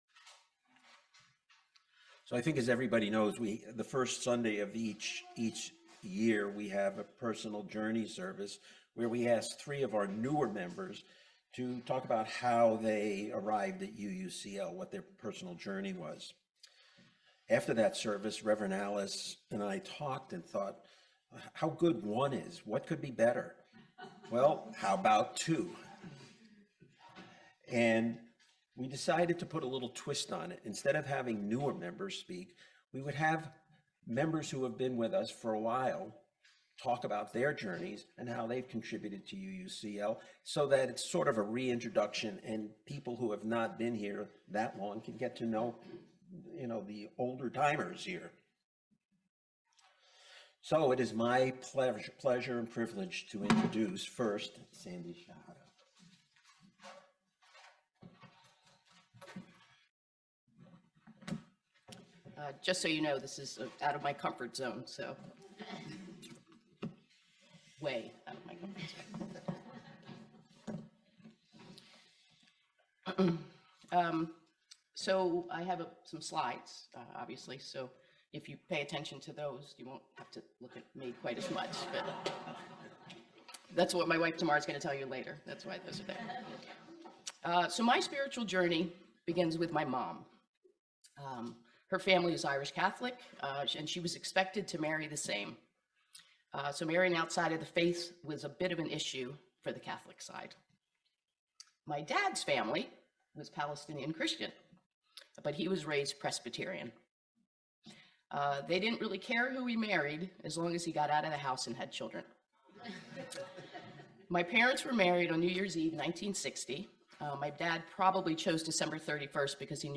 This sermon documents a Unitarian Universalist service where long-term members share their personal spiritual histories to help the congregation connect across generations.